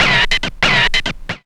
5404R SCRACH.wav